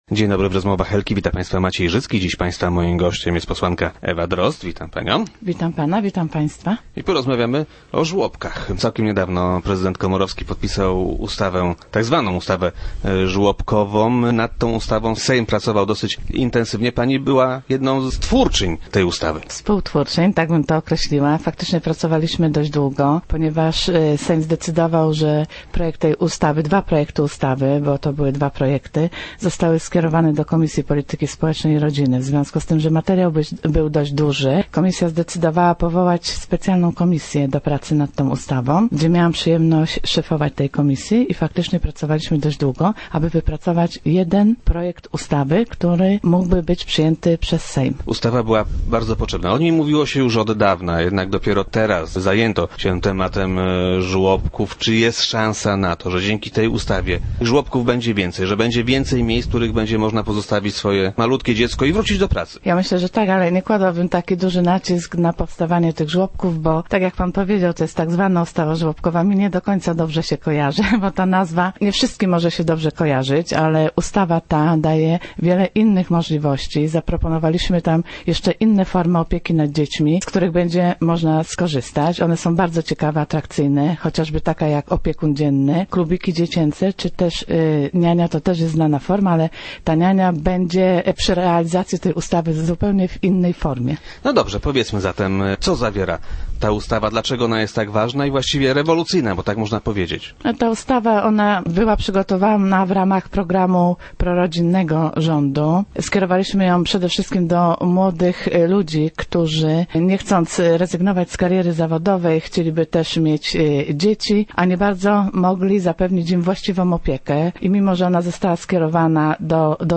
Dziś Ewa Drozda była gościem Rozmów Elki.